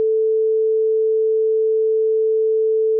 WAV file with a sine wave tone in it.
tone.wav